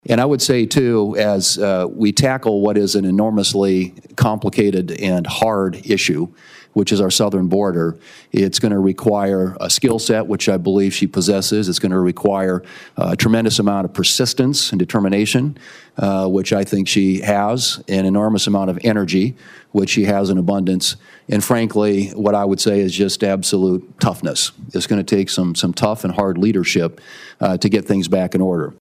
WASHINGTON, D.C.(DRGNews)- South Dakota Governor Kristi Noem answered questions from members of the United States Senate Homeland Security and Governmental Affairs Committee Friday during her confirmation hearing to become President-elect Donald Trump’s Secretary of Homeland Security.
South Dakota Senator and Senate Majority Leader John Thune gave some introductory remarks to the committee.